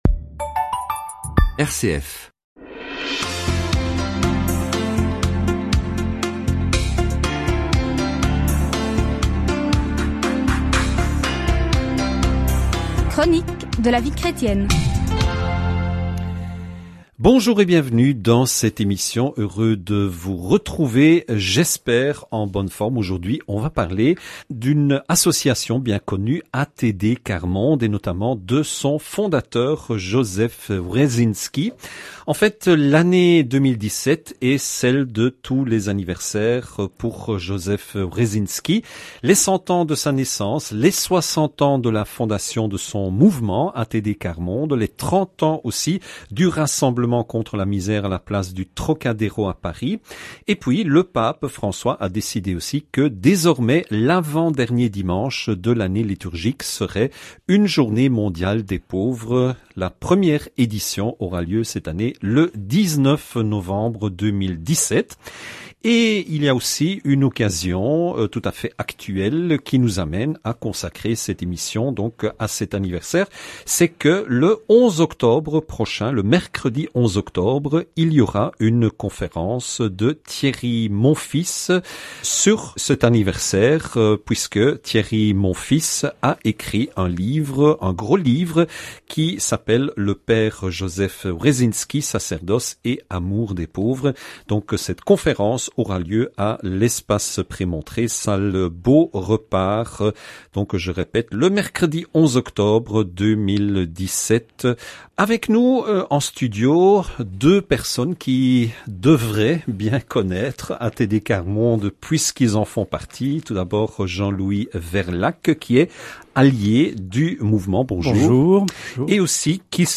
Reportage de RCF